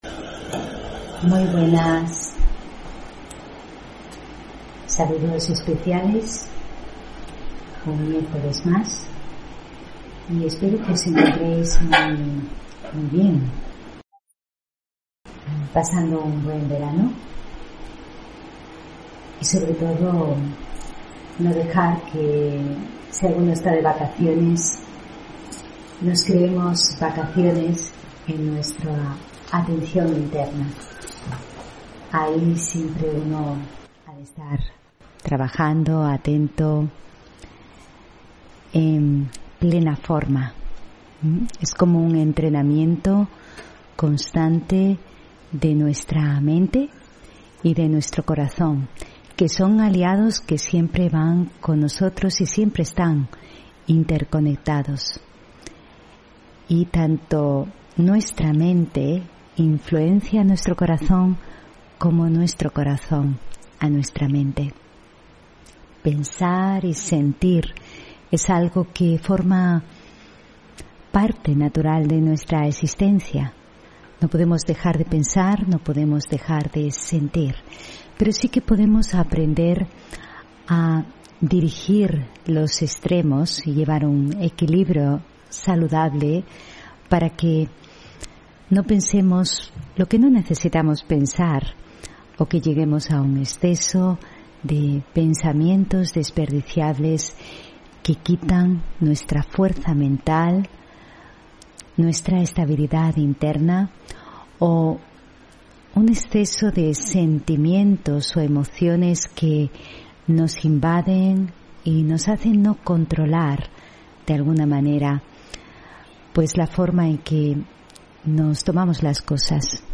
Audio conferencias
Meditación Raja Yoga y charla: Una mente libre y un corazón compasivo (28 Julio 2021) On-line desde Sevilla